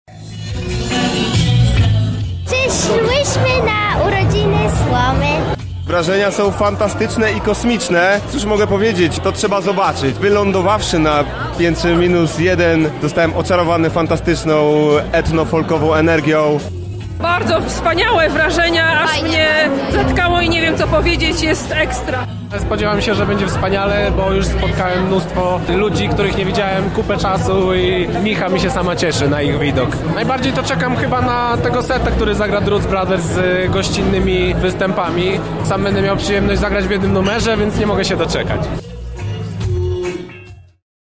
O swoich wrażeniach z festiwalu opowiadają uczestnicy.